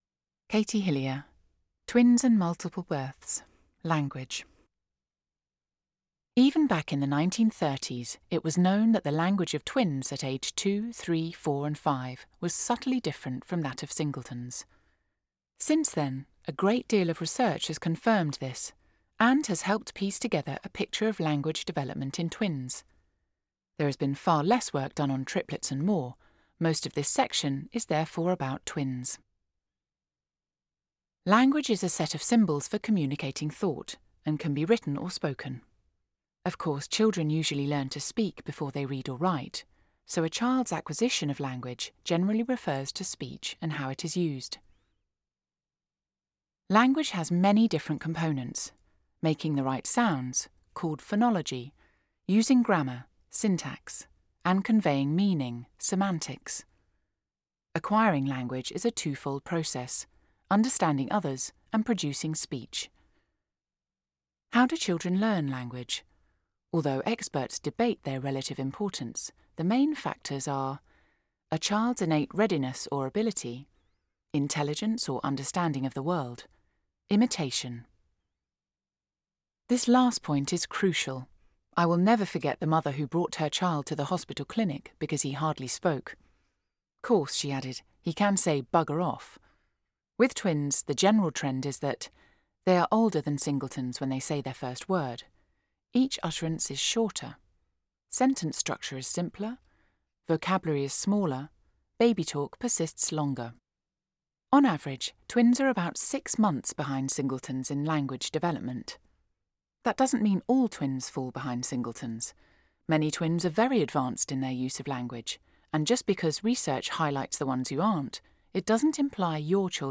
• Female
• Standard English R P